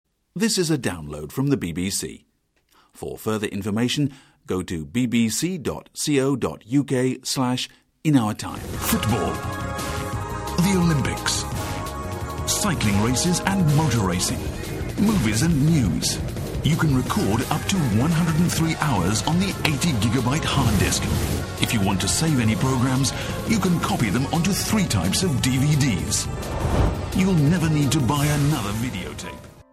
イギリス英語 男性｜ナレーター紹介｜東京IMナレーター｜英語や韓国語をはじめ世界各国の言語でのナレーションをご提供